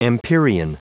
Prononciation du mot empyrean en anglais (fichier audio)
Prononciation du mot : empyrean